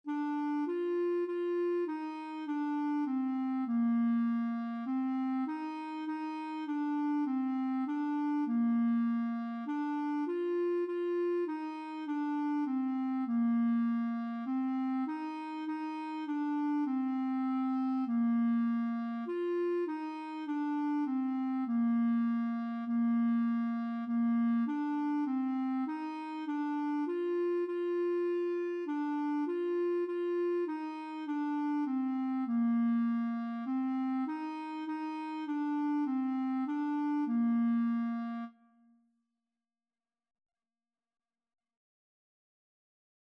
4/4 (View more 4/4 Music)
Bb4-F5
Clarinet  (View more Beginners Clarinet Music)
Classical (View more Classical Clarinet Music)